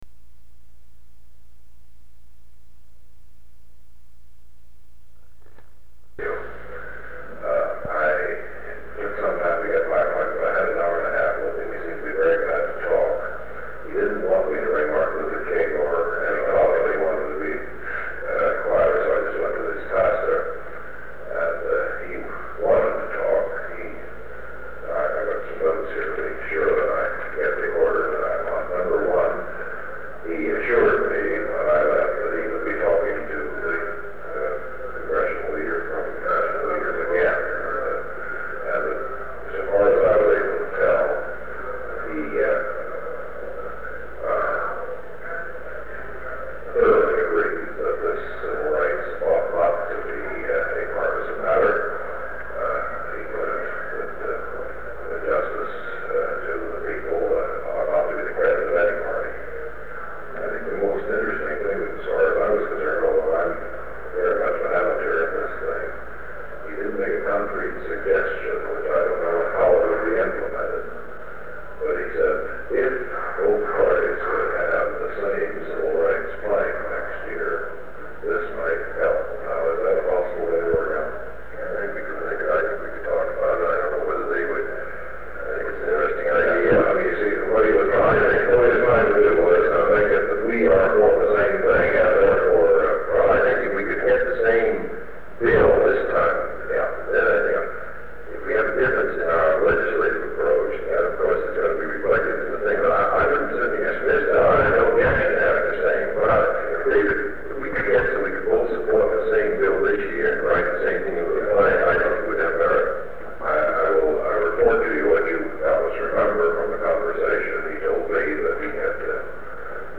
Sound recording of a meeting held on September 30, 1963, between President John F. Kennedy and former President of the National Council of Churches Eugene Carson Blake. They discuss Mr. Blake’s recent meeting with former President Dwight D. Eisenhower on the civil rights situation and support in Congress for upcoming civil rights bills.
Secret White House Tapes | John F. Kennedy Presidency Meetings: Tape 113.